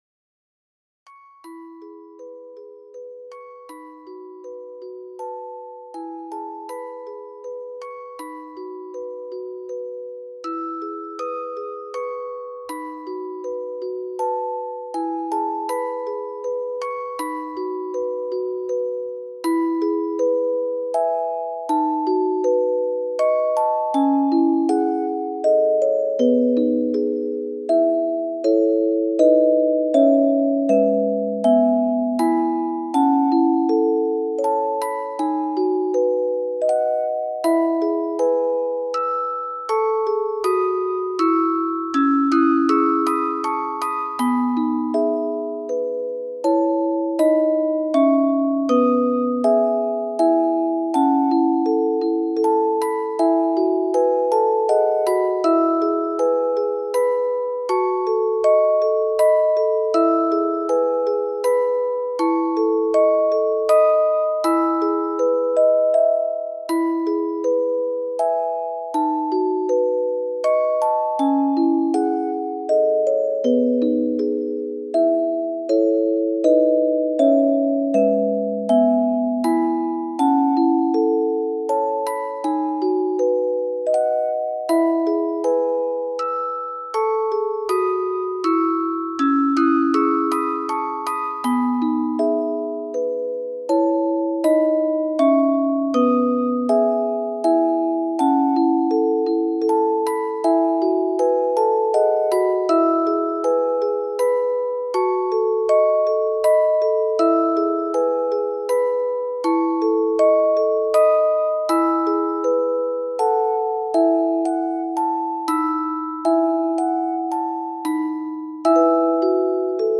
落ち着いたイメージ, オルゴール素材.